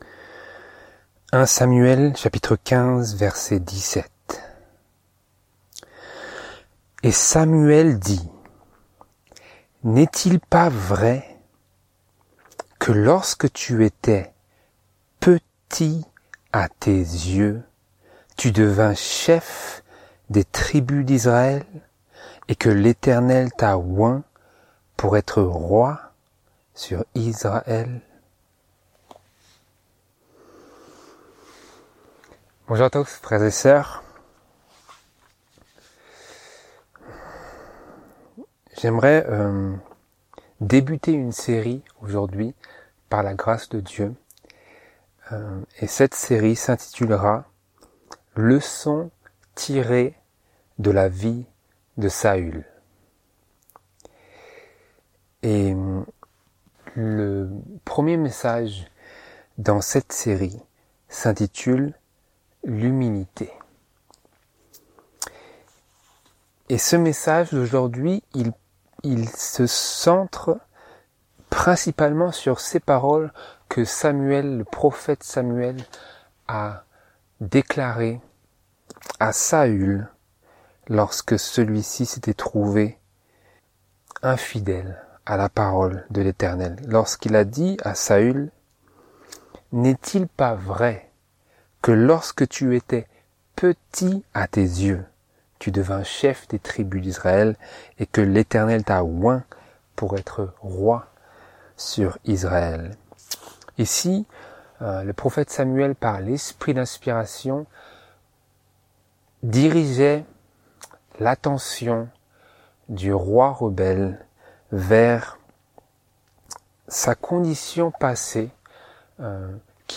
Laodicée – Sermons du Sabbat